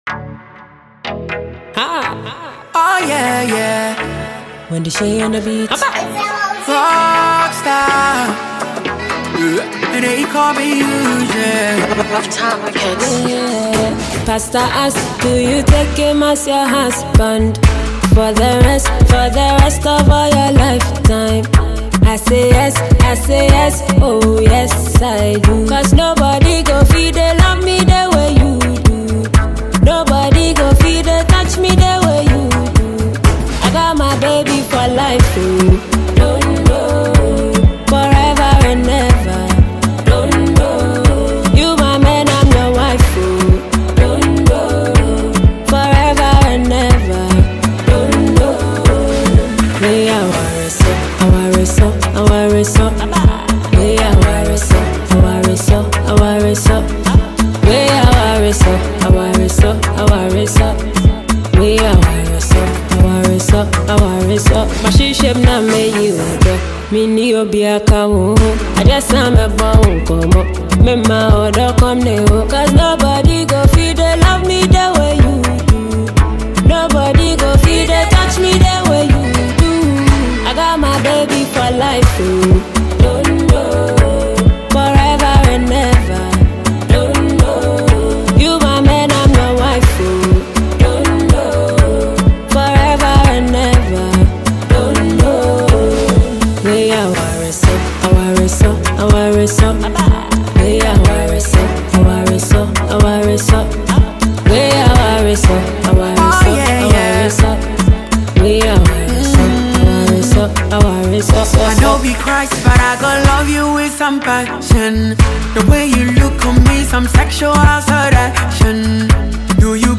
Award Winning versatile singer
Xmas banger
award-winning highlife act